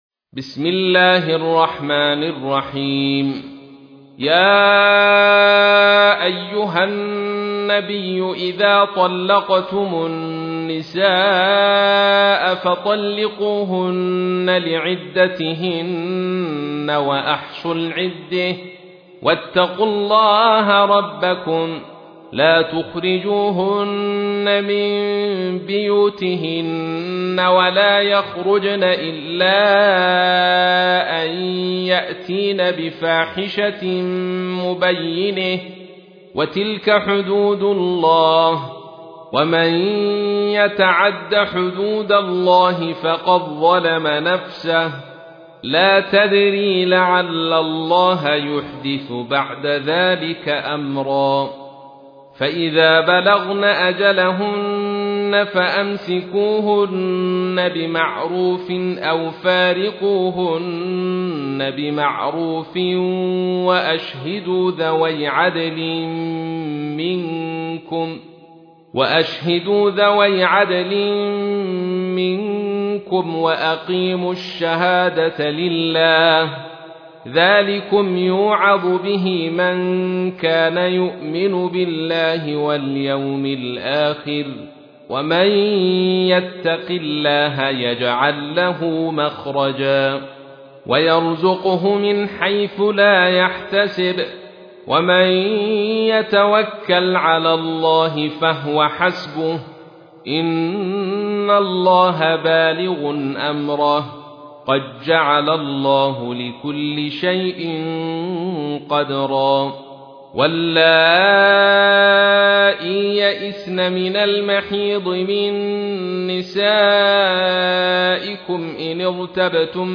تحميل : 65. سورة الطلاق / القارئ عبد الرشيد صوفي / القرآن الكريم / موقع يا حسين